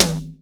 TOM     1A.wav